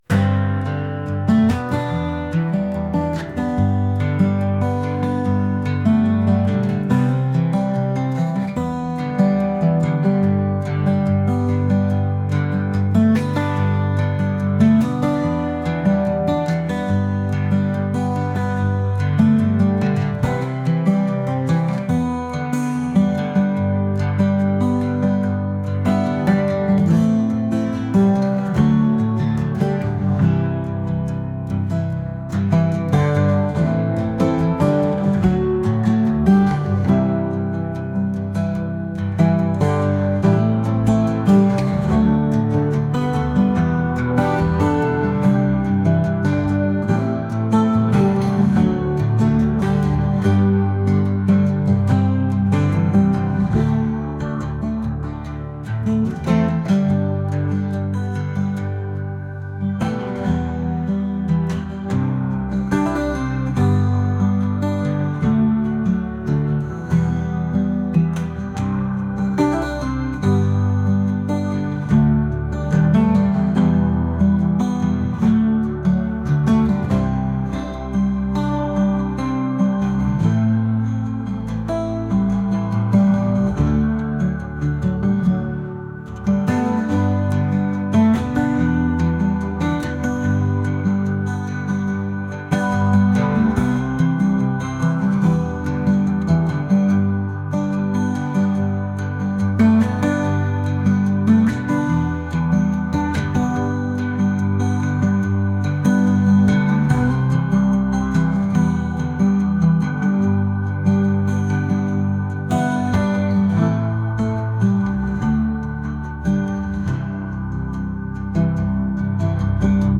indie | folk | acoustic